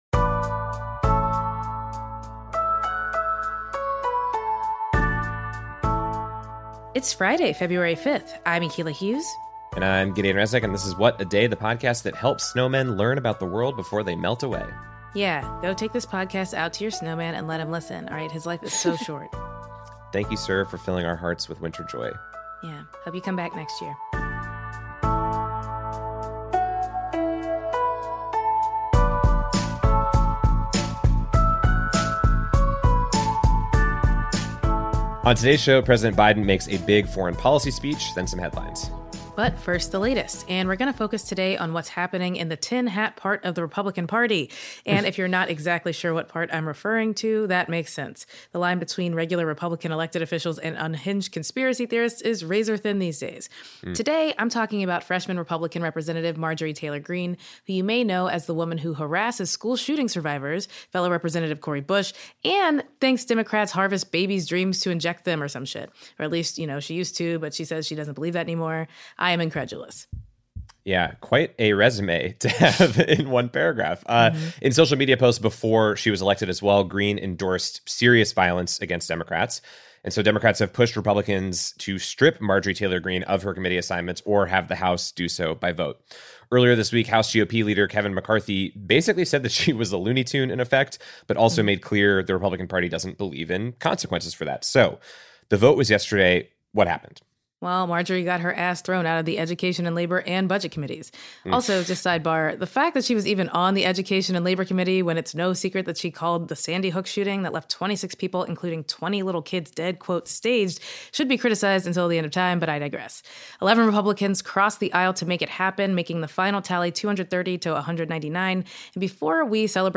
We spoke to California Representative Ro Khanna about the decision, what led to it, and what it means for progressives and activists who want their voices heard on issues of US foreign policy.